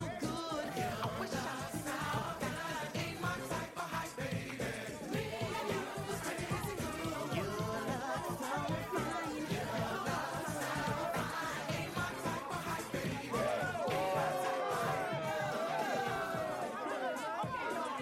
House Party Dance Music Bouton sonore